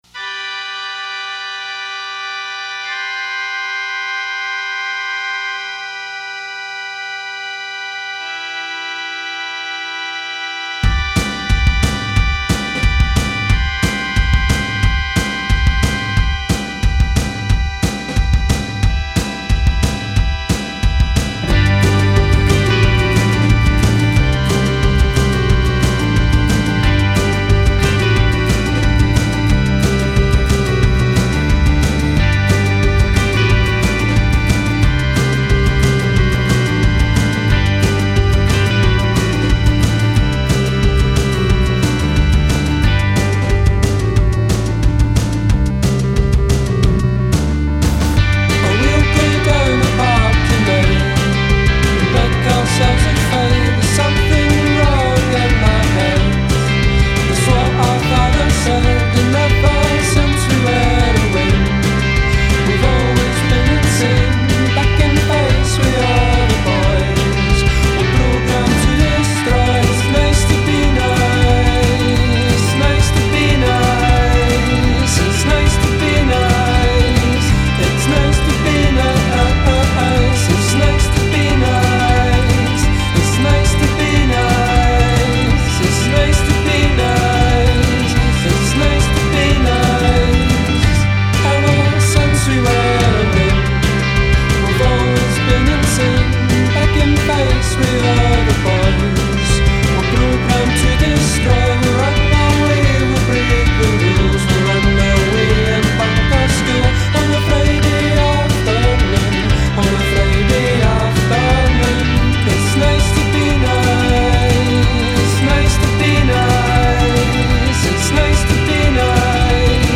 fantastico indiepop
con qualche innesto di elettronica.